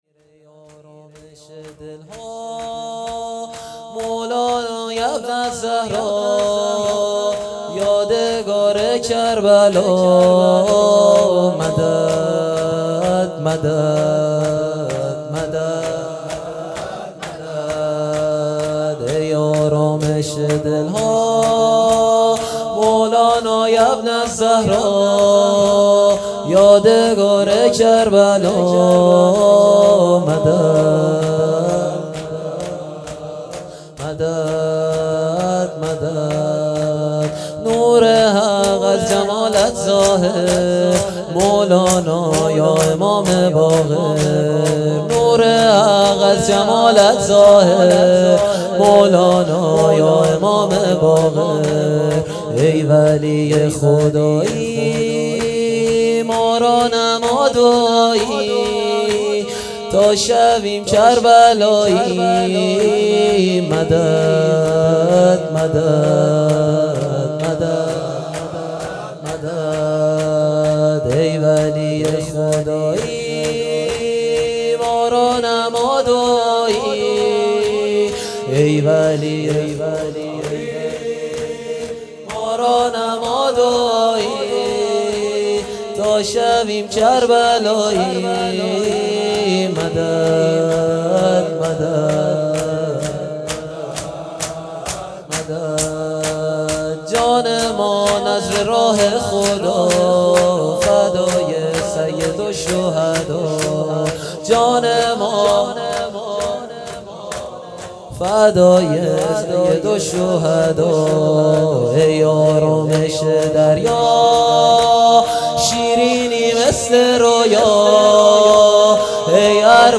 شور
شهادت امام محمد باقر (علیه السلام) "دعای کمیل"